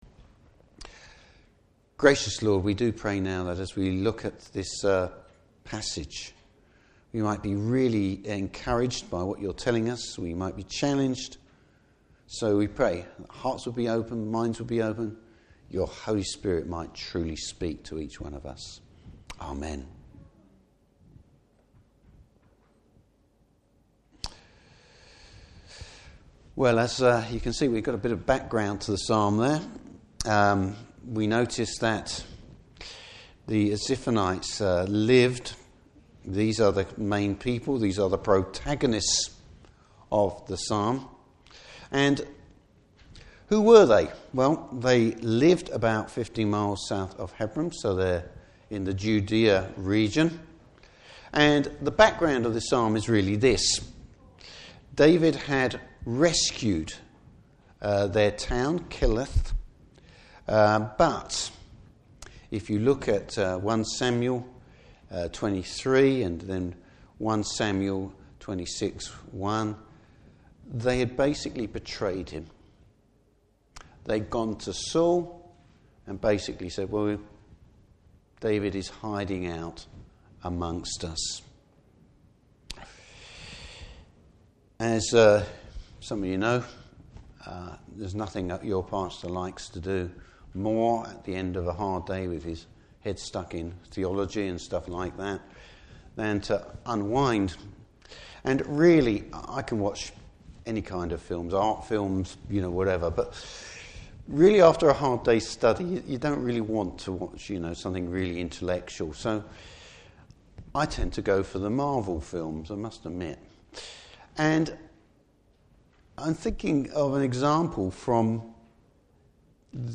Service Type: Morning Service The situation is desperate, but David knows where to put his faith!